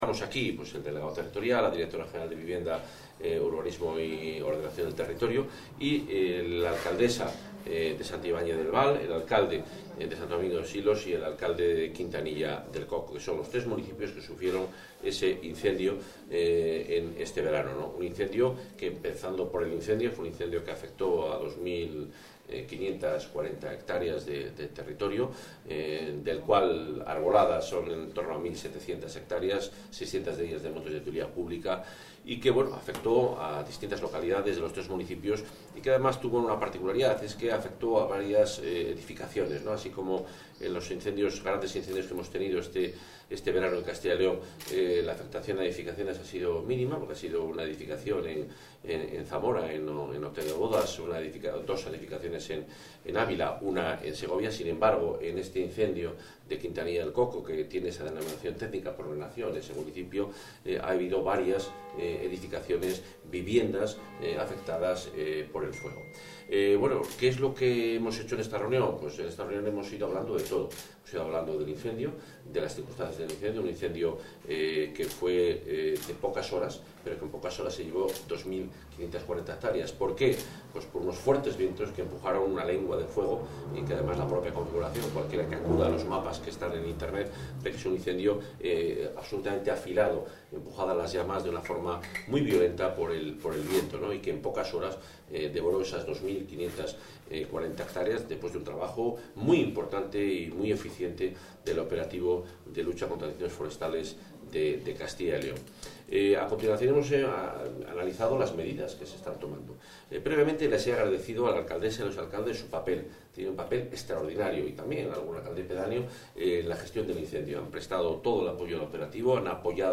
Audio consejero de Medio Ambiente, Movilidad y Ordenación del Territorio.